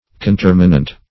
Search Result for " conterminant" : The Collaborative International Dictionary of English v.0.48: Conterminant \Con*ter"mi*nant\, a. Having the same limits; ending at the same time; conterminous.